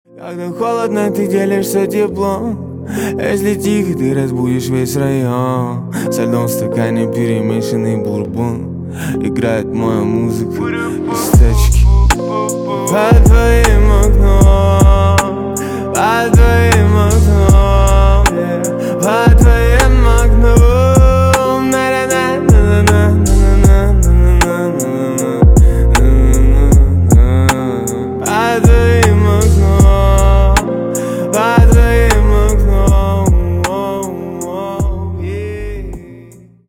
на русском на девушку грустные